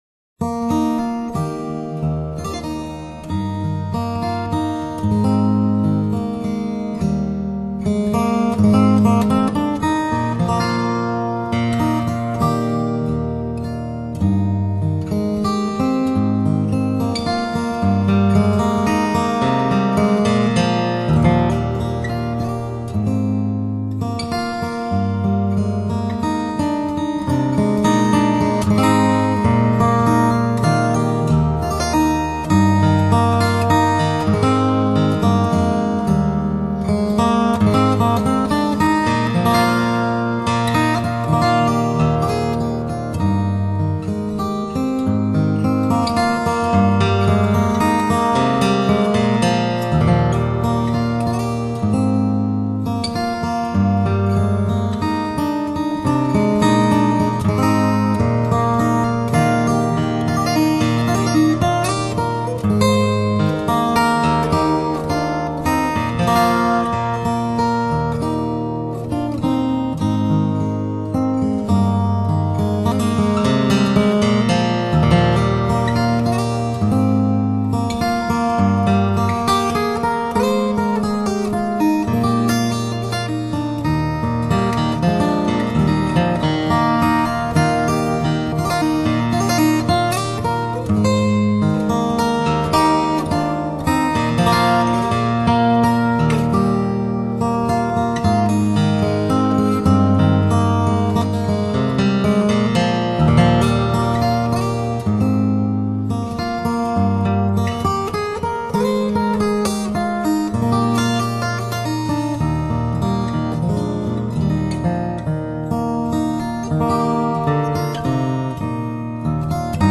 包括有竖琴、吉它、凯尔特小提琴、风笛，还有柔美女声的吟唱等
历史与灵魂的叠合，轻柔的掀示为尘世蒙蔽的真实潜意识；温暖透明的歌声及竖琴的温柔婉约、宛若置身天堂边境。